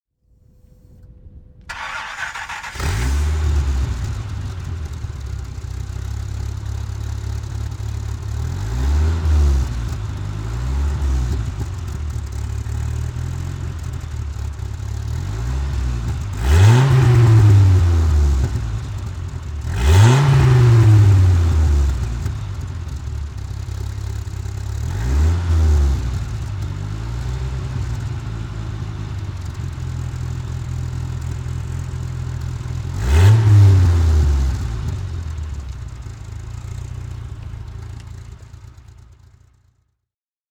To give you an impression of the variety, we have selected ten different engine sounds.
Chrysler Viper RT/10 (1995) - Starting and idling
Chrysler_Viper_1995.mp3